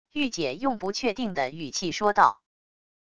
御姐用不确定的语气说道wav音频